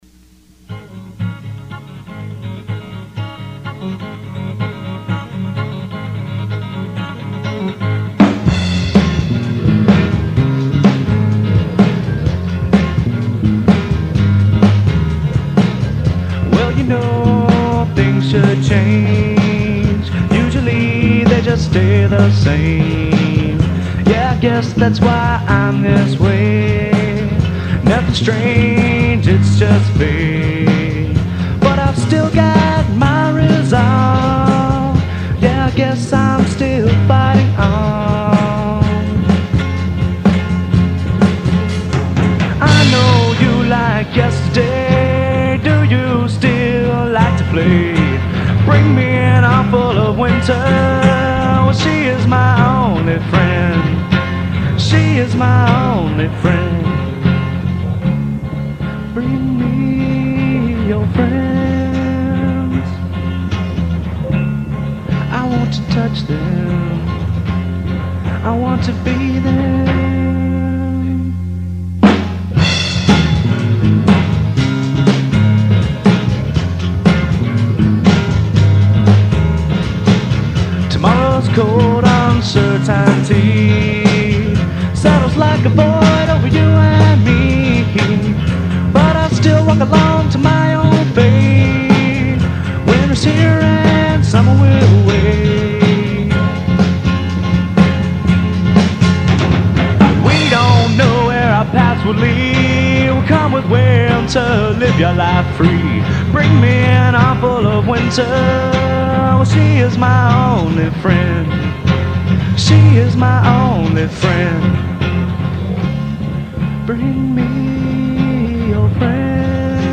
1992-1993 Demo's
Recorded at Zombie Birdhouse, Oxford, MS
Bass
Drums
Acoustic Guitar
Electric Guitar
Vocals